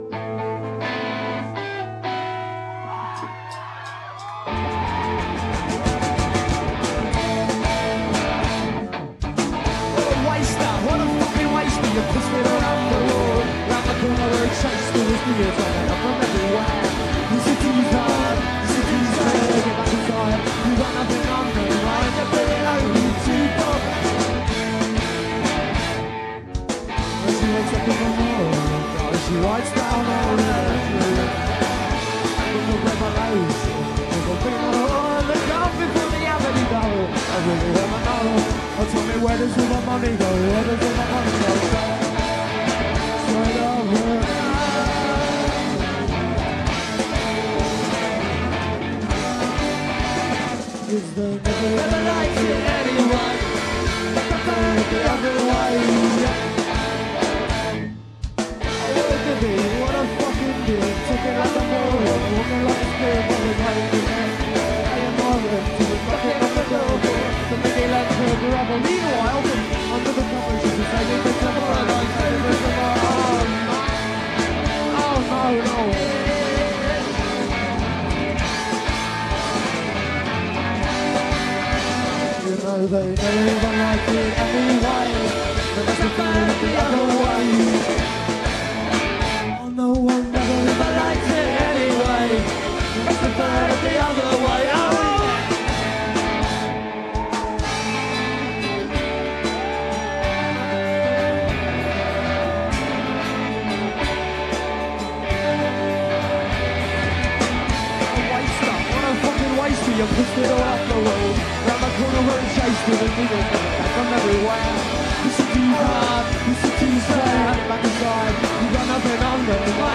CBGB - NYC